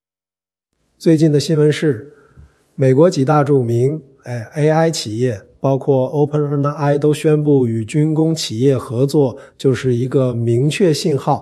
f5tts - F5-TTS wrap module